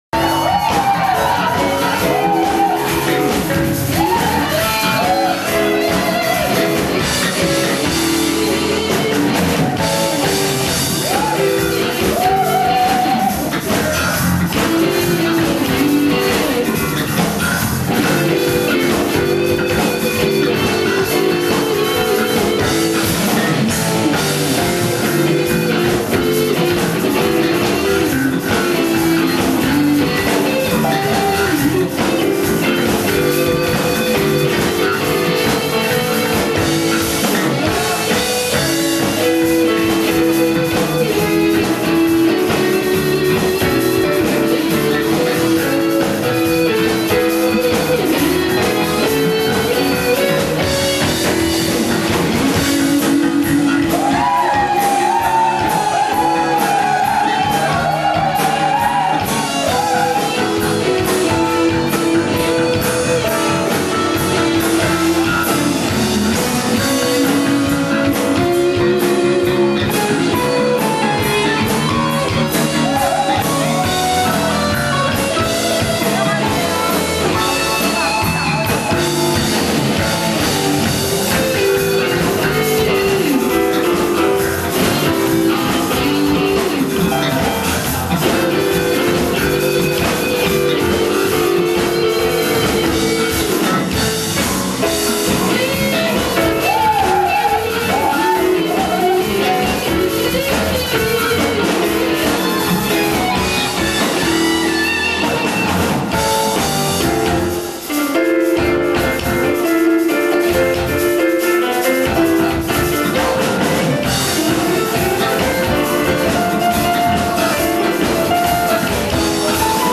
2006-10-03 오후 9:48:00 여름에 공연했던 음원입니다~^^; ㅋ 완벽하진 못하지만..
전 베이스입니다^^